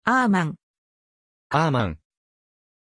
Pronunciation of Arman
pronunciation-arman-ja.mp3